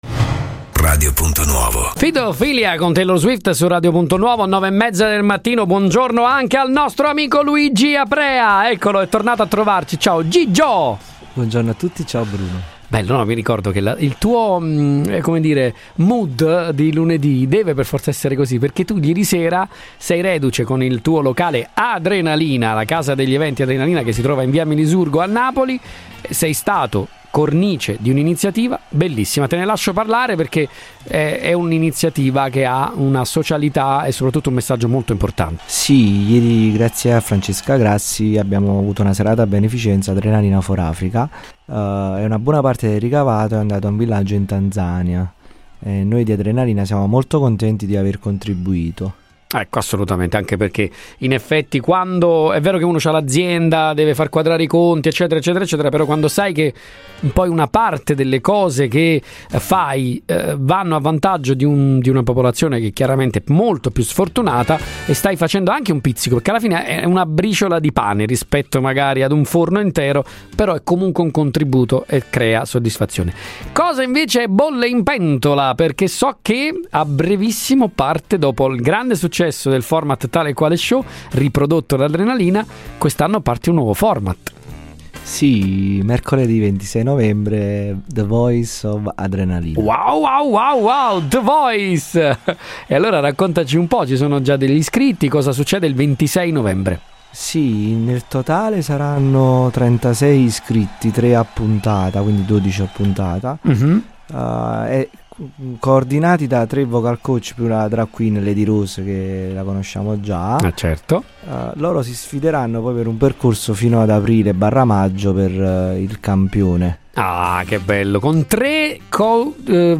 è intervenuto a Radio Punto Nuovo per raccontare le recenti iniziative che uniscono divertimento, musica e un importante impegno sociale.